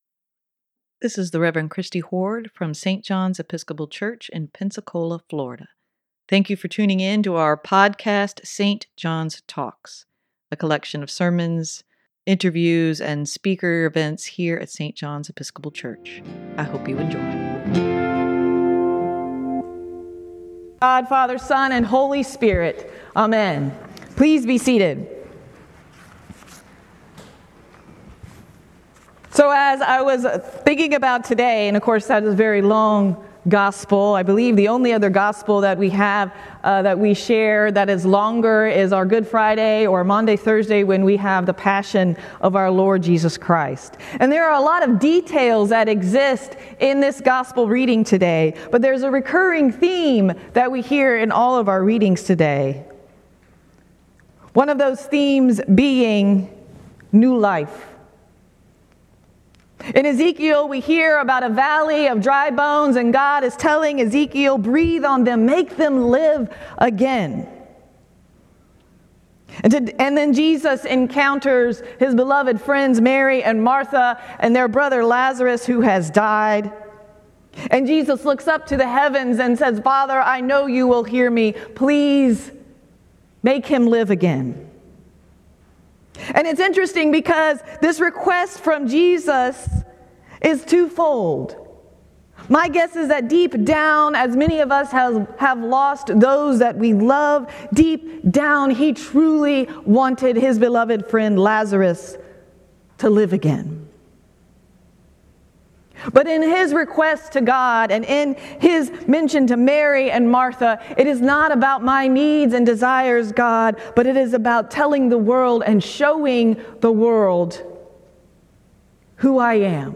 sermon-3-26-23.mp3